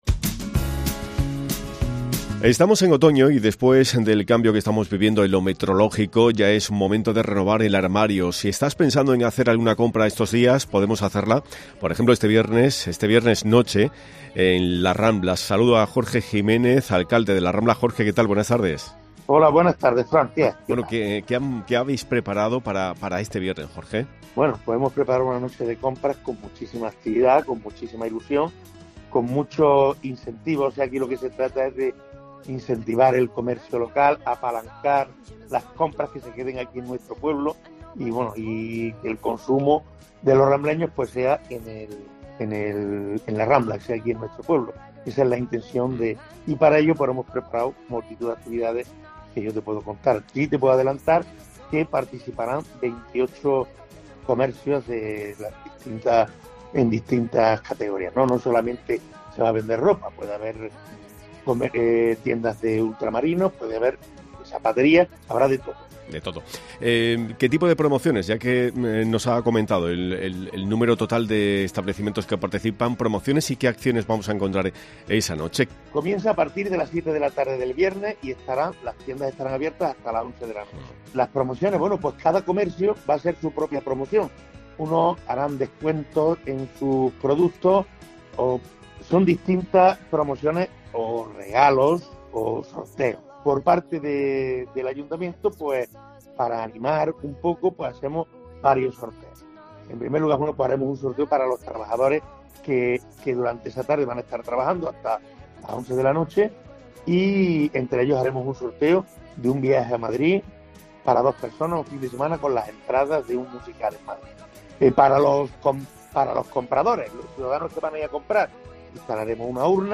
Como ha indicado su alcalde en COPE, Jorge Jiménez (PP), "es el mejor momento para hacernos con todo aquello que nos hace falta, y no solo estamos hablando de ropa, porque obtendremos importantes descuentos y ofertas a todos aquellos que se acerquen a las tiendas de nuestra localidad".